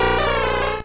Cri de Tygnon dans Pokémon Or et Argent.